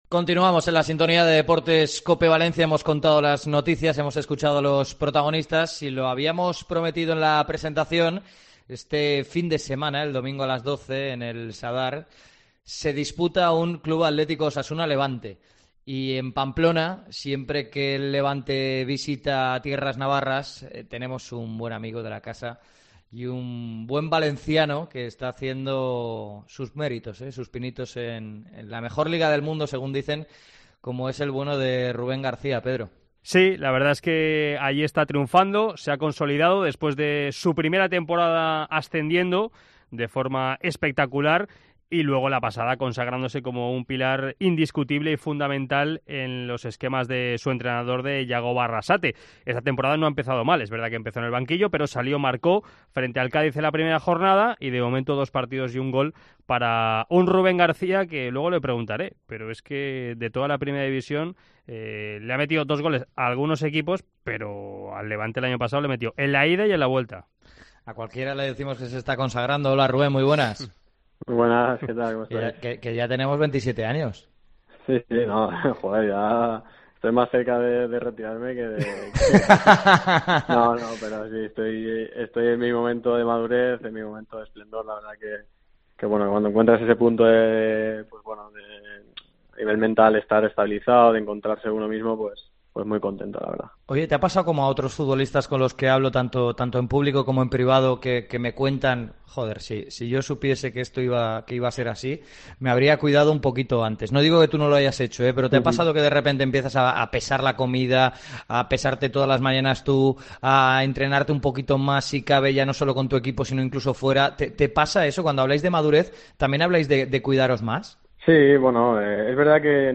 ENTREVISTA A RUBEN GARCÍA